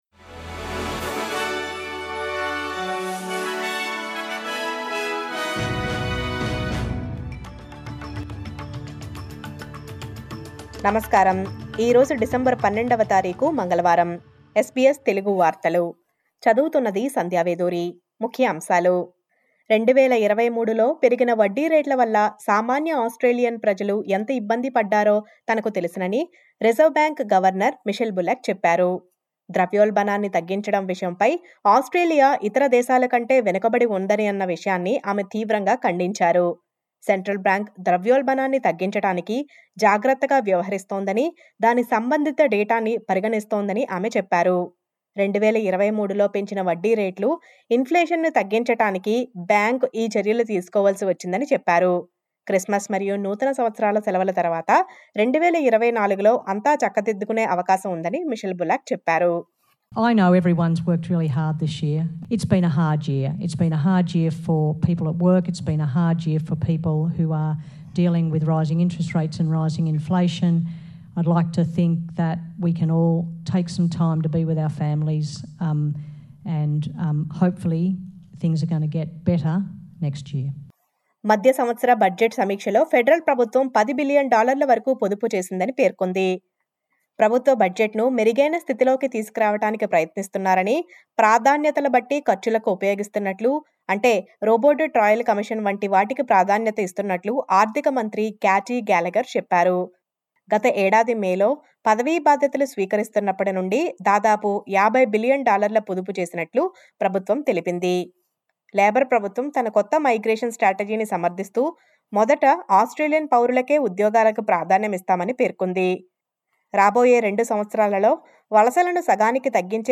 SBS తెలుగు వార్తలు.